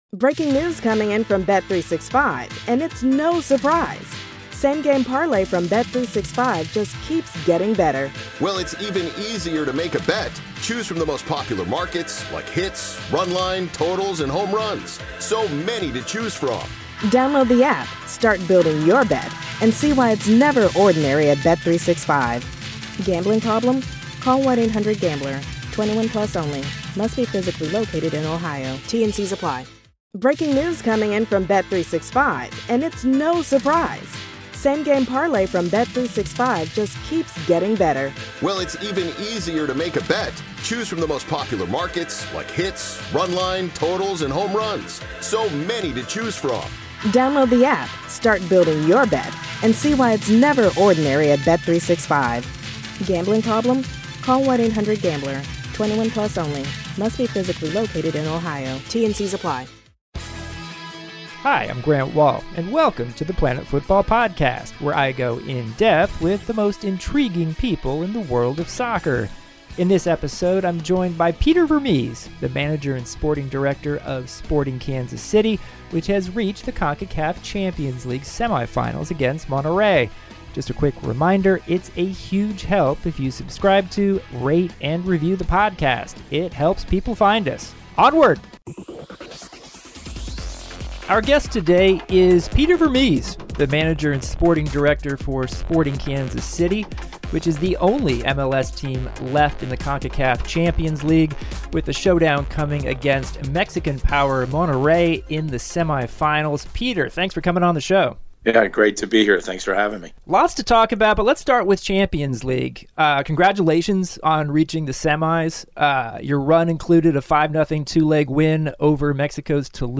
Grant has a wide-ranging conversation with Peter Vermes, the manager and sporting director of Sporting Kansas City, the only MLS team to reach the semifinals of this year's CONCACAF Champions League. ...